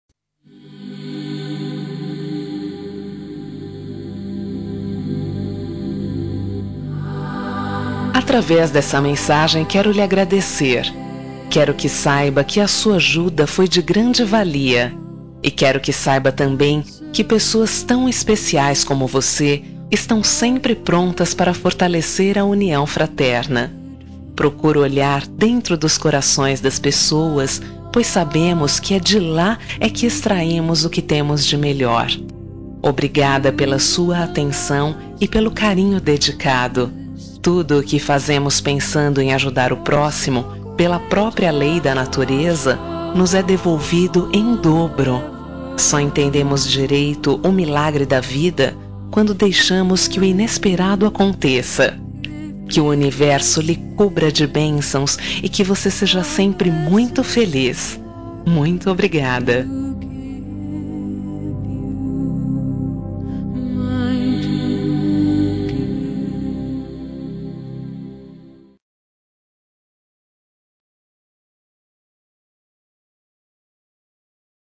Telemensagem de Agradecimento pela ajuda – Voz Feminina – Cód: 01